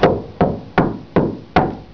Knocking
Knocking.wav